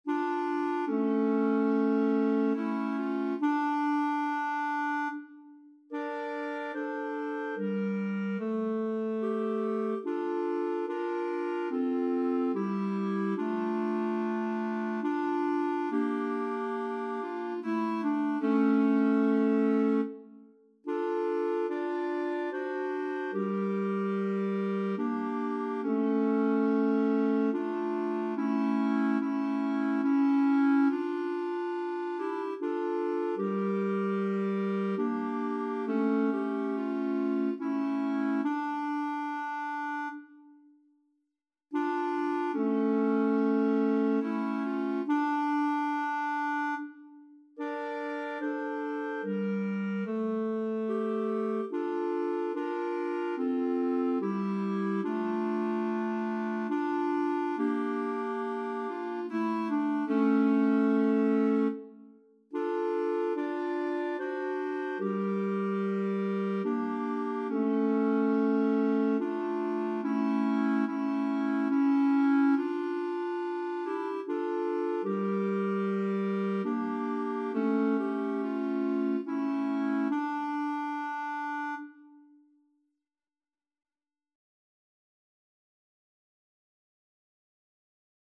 Title: Perdão, Meu Deus Composer: Pedro Sinzig Lyricist: Number of voices: 1v Voicing: Unison Genre: Sacred, Sacred song
Language: Portuguese Instruments: Organ